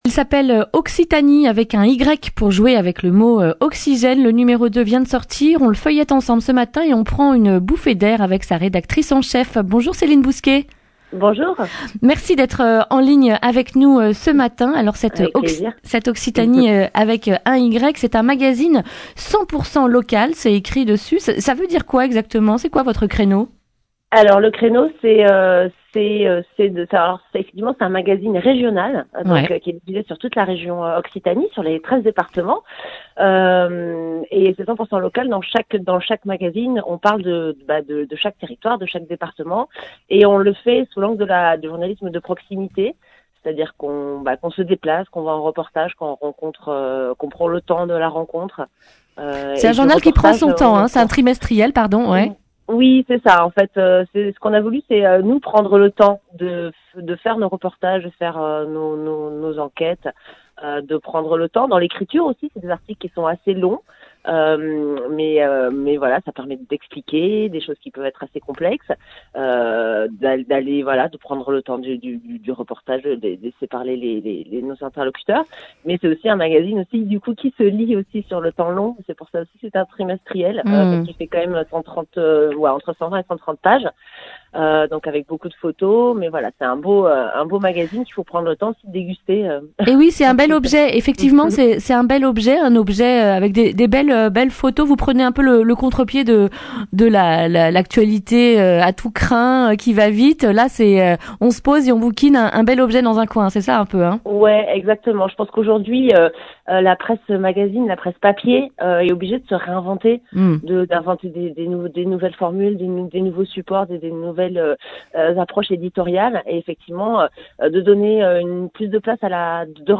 mercredi 9 octobre 2019 Le grand entretien Durée 10 min